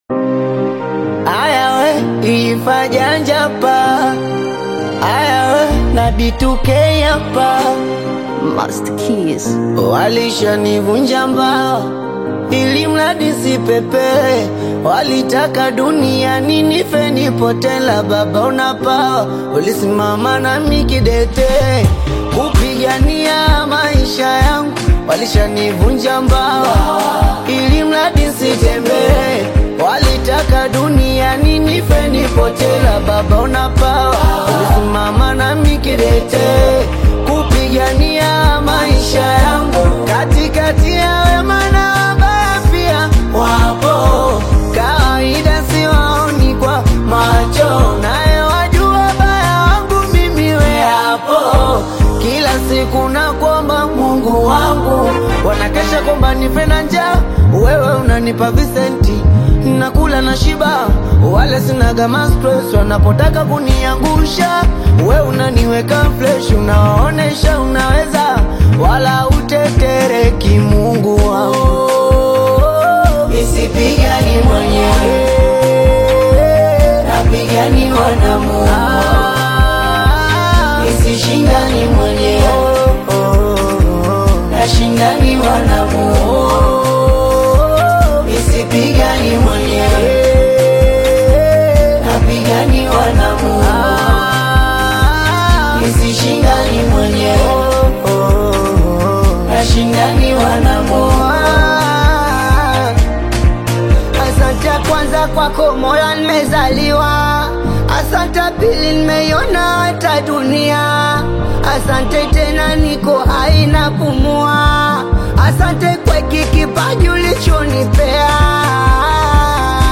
Elevate your mood with the infectious rhythms